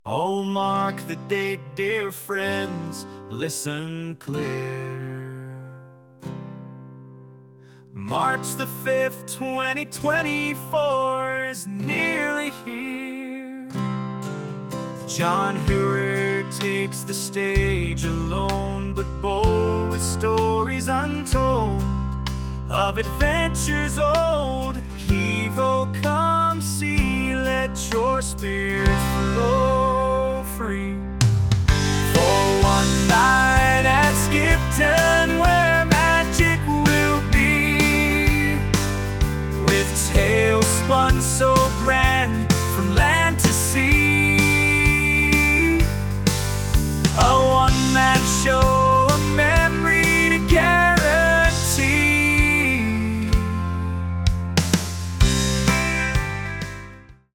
Skipton-Sea-Shanty.mp3